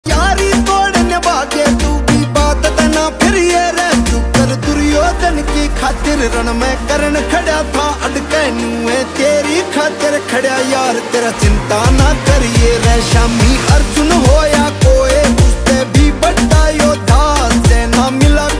Enjoy the catchy Haryanvi beat and vibrant vibes.